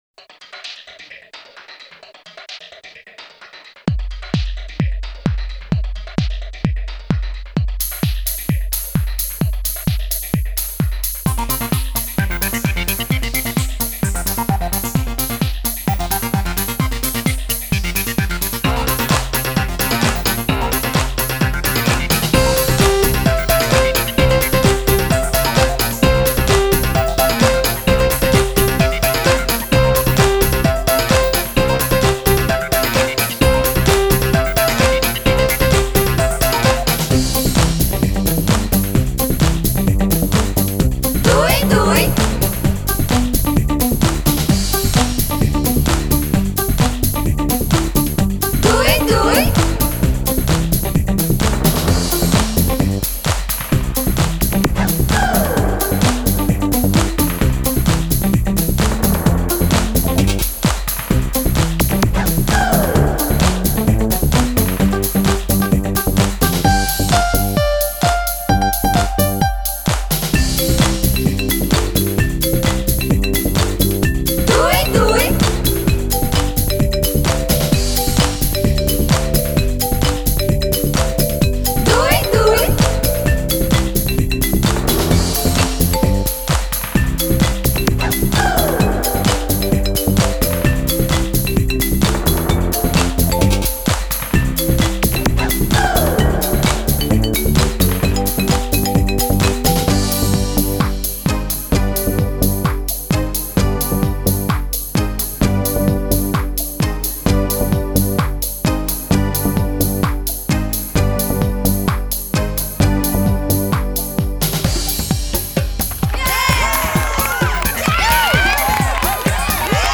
SchouderCom - Zonder zang
Lied-7-Online-instrumentaal-.mp3